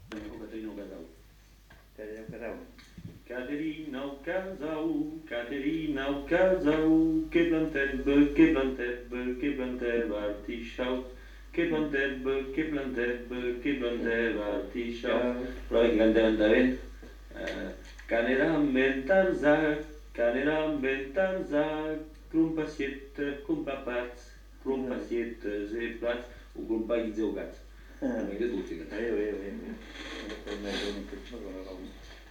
Aire culturelle : Petites-Landes
Lieu : Lencouacq
Genre : chant
Effectif : 1
Type de voix : voix d'homme
Production du son : chanté
Danse : varsovienne